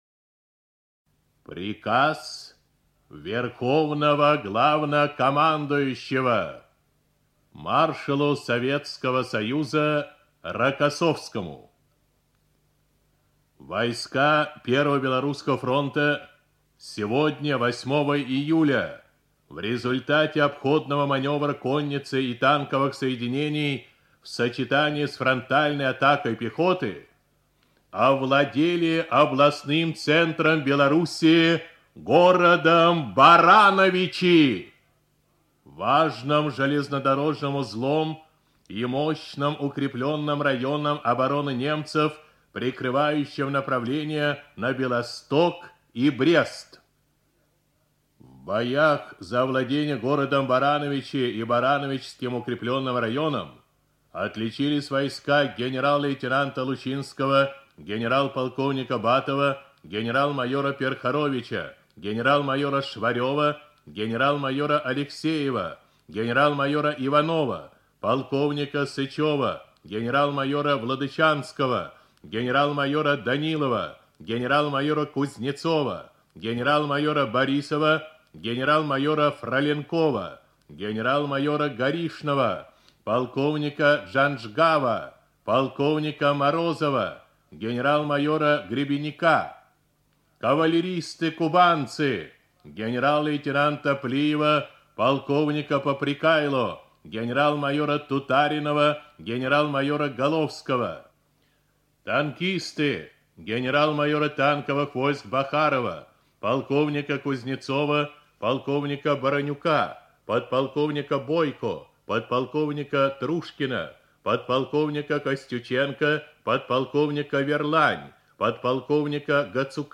Текст читает диктор Всесоюзного радио, народный артист РСФСР и СССР Левитан Юрий Борисович